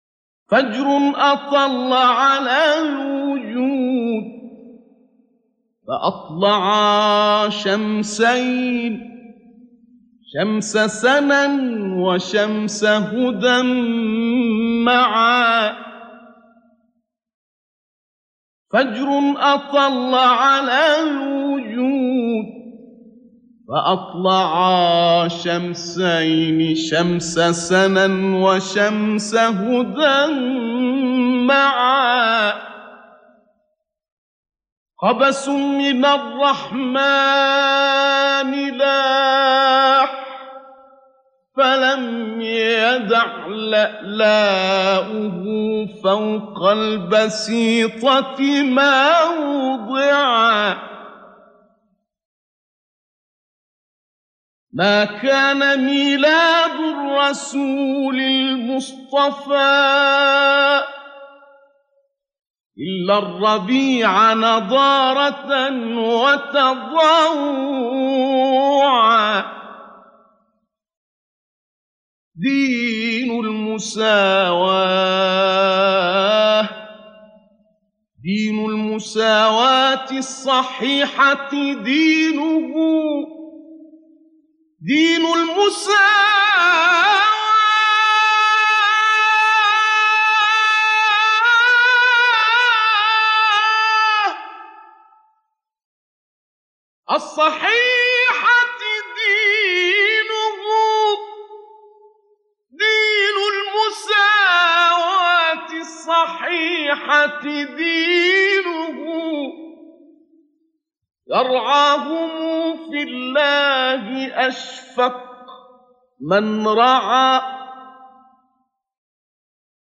ابتهال سید نقشبندی